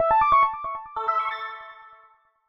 welcome_sfx.ogg